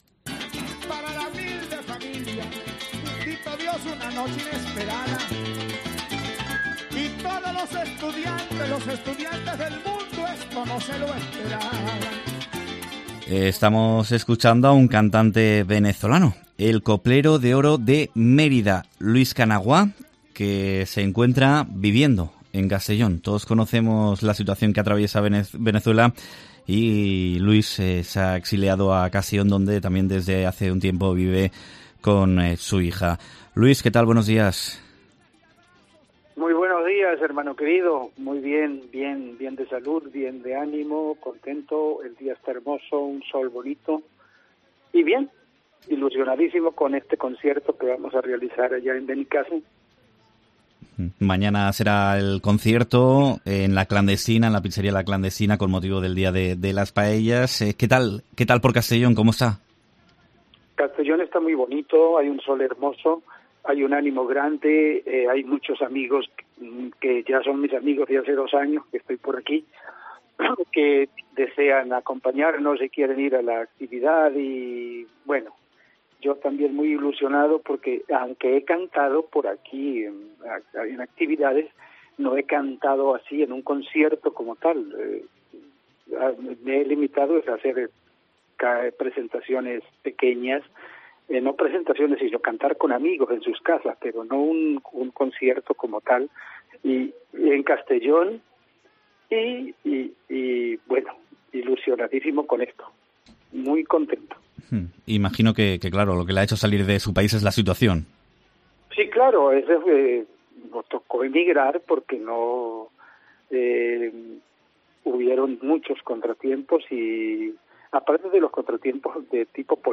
Entrevista al cantante venezolano residente en Castellón